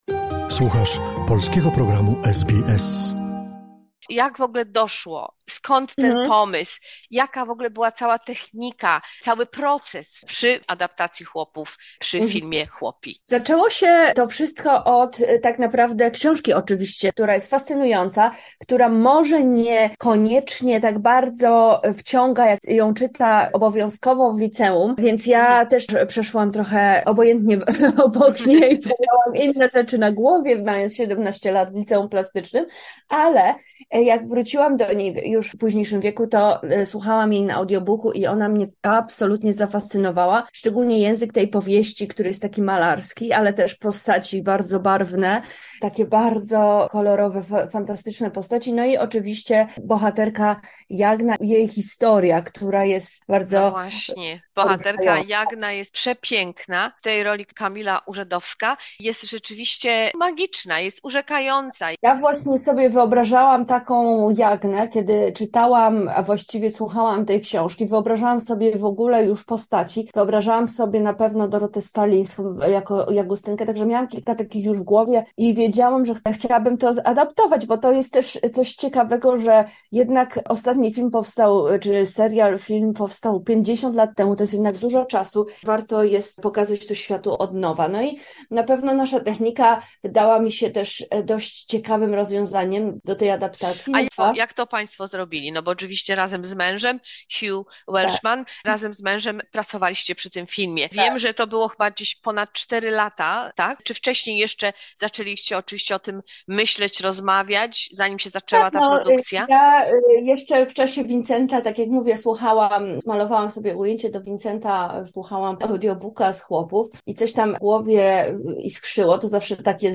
Fragment rozmowy z reżyserką DK Welchman o procesach powstania filmu. Jest to kolejna filmowa adaptacja nagrodzonej Noblem powieści Władysława Reymonta ,,Chłopi"!